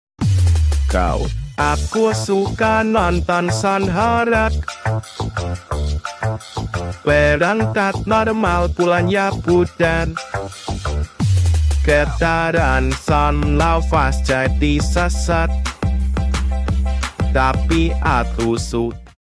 versi tung tung tung sahur